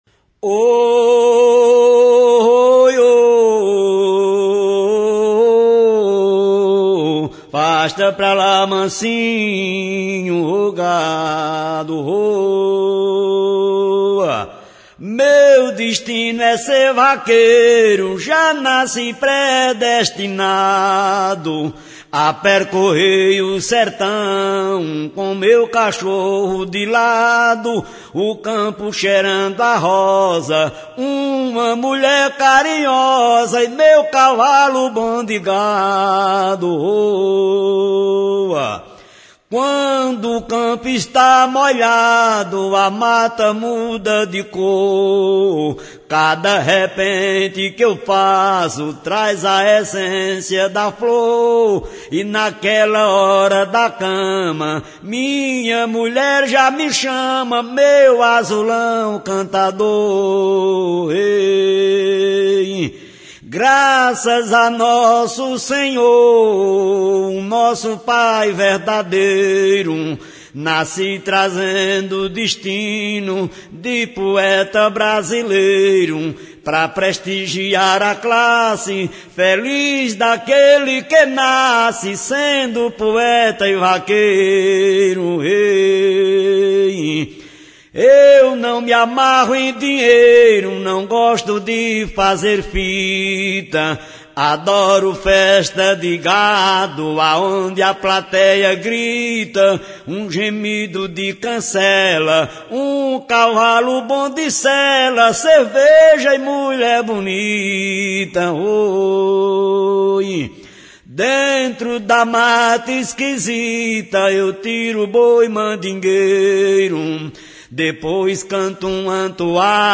toada